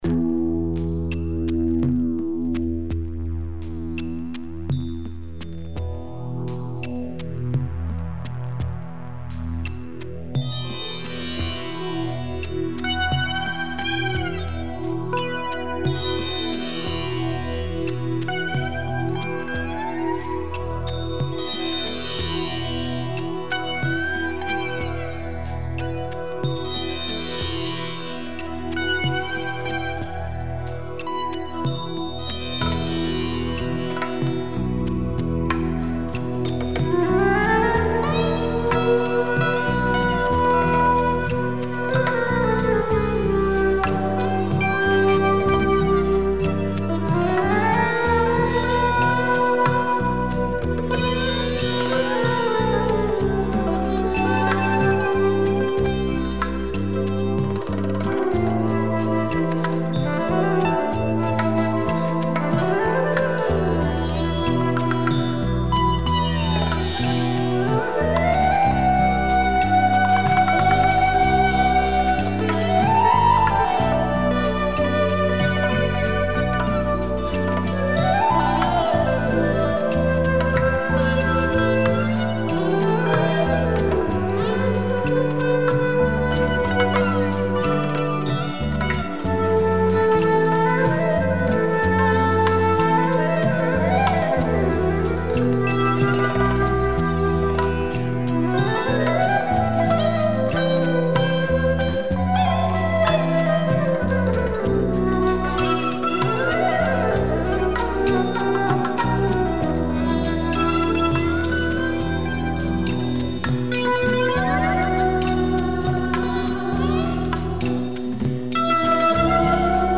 Very obscure Spanish musical
smokey instrumental
sitar